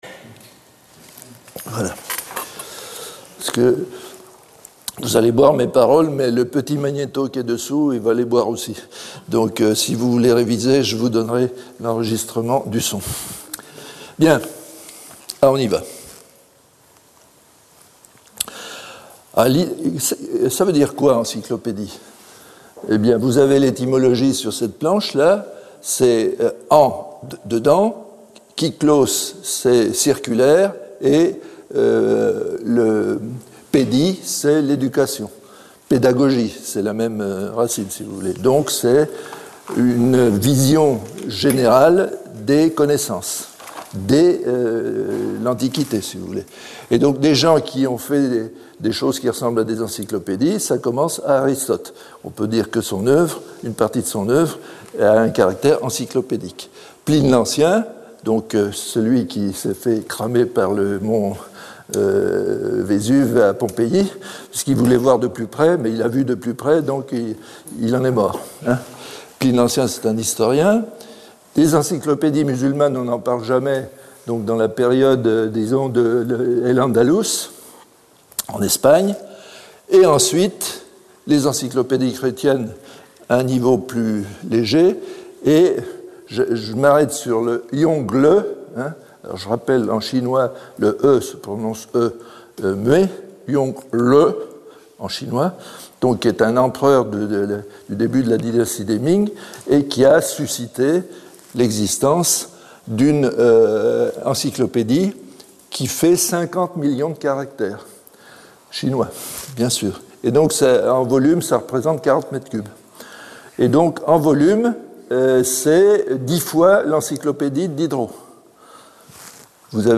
Contenu en libre service Encyclopédie Origines et filiations Encyclopédie Prospectus Encyclopédie histoire éditoriale Encyclopedie Articles et renvois Enregistrement de l'exposé sur l'encyclopedie de Diderot